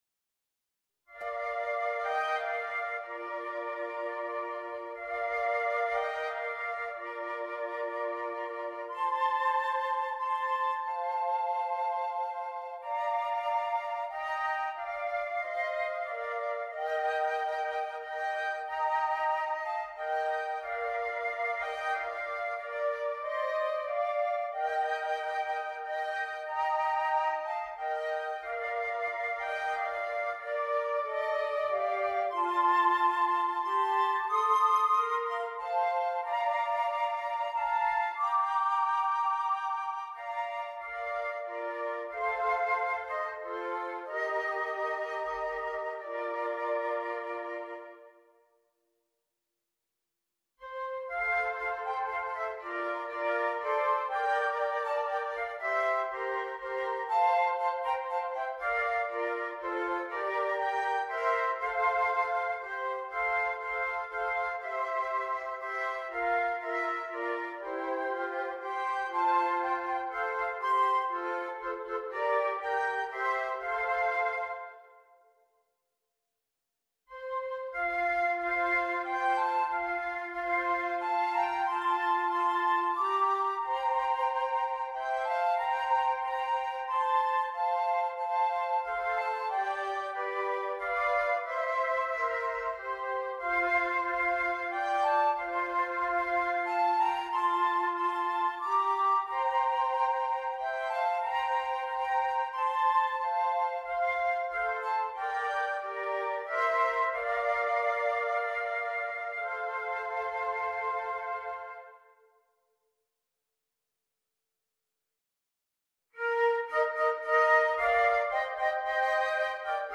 5 Easy Carols for Young Musicians - Flute Trio